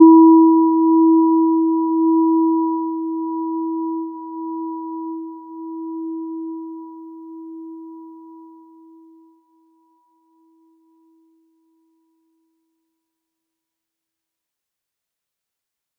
Gentle-Metallic-1-E4-p.wav